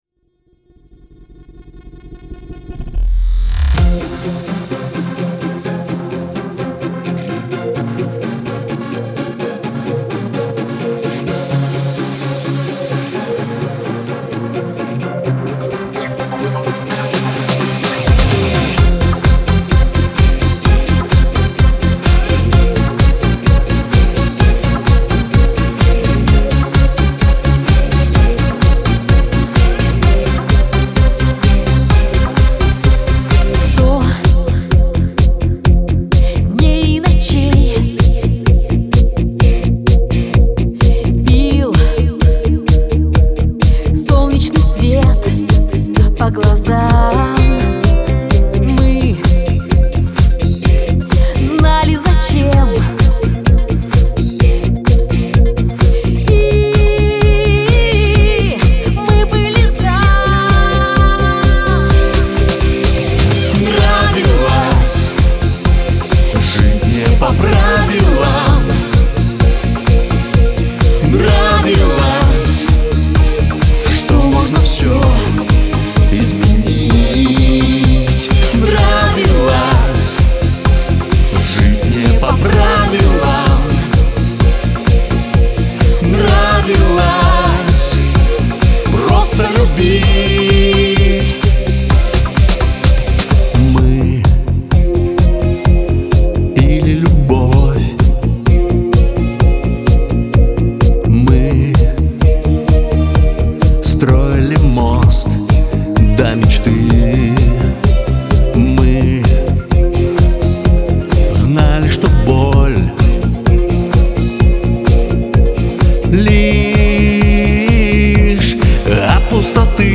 Лирика , pop , дуэт